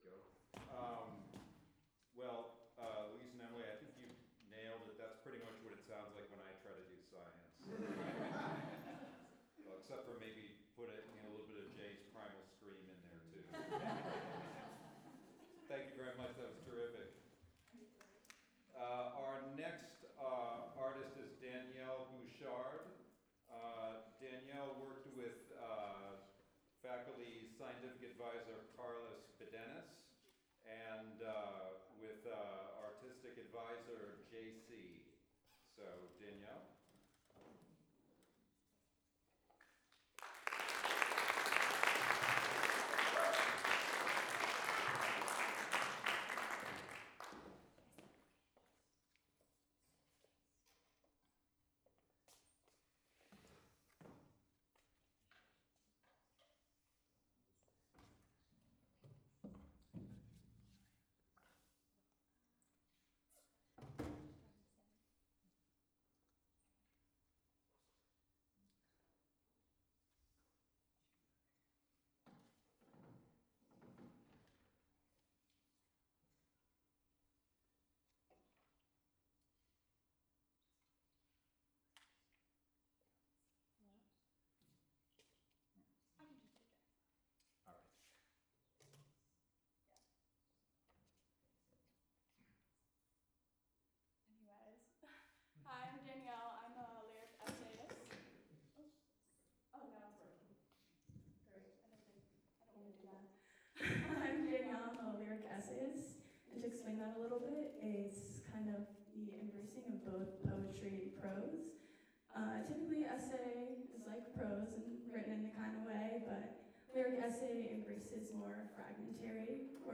Performance Recording